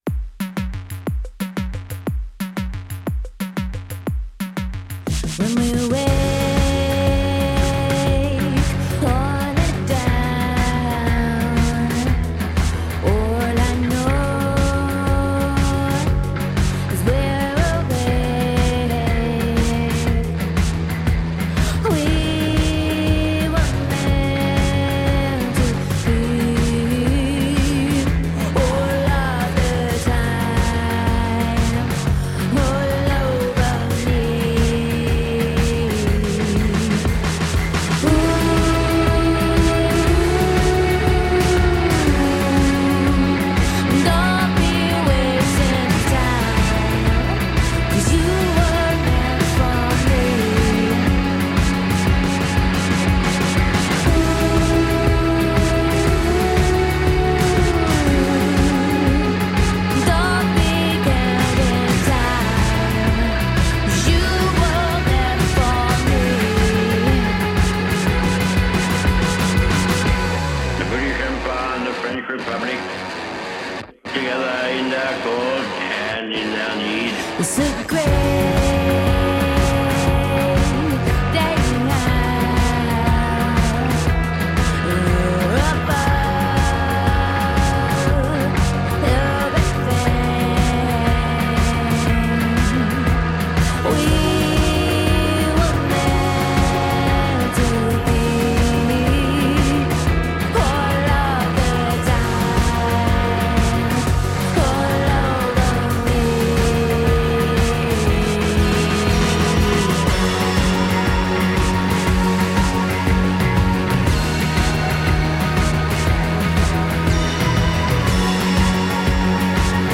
Groovy, french downtempo electro-rock songs.
Tagged as: Electro Rock, Other, Woman Singing Electro Pop